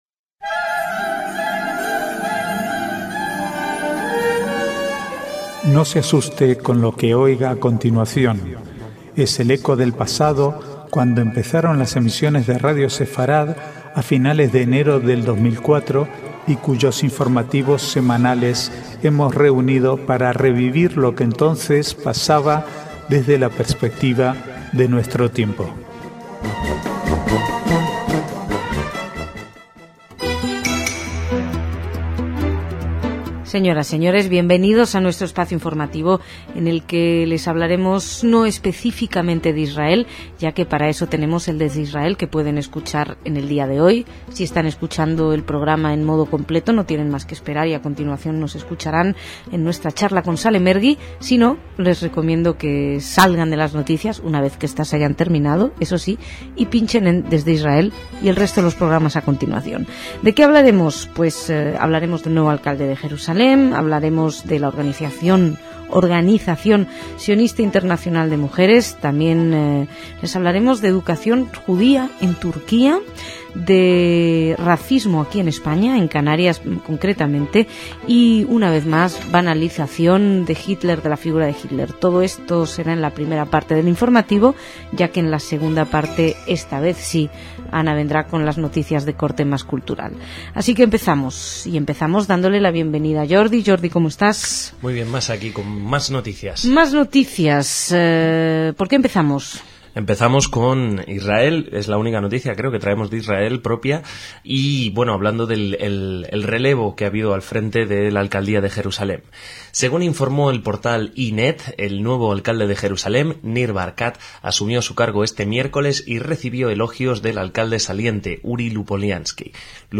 Archivo de noticias del 5 al 10/12/2008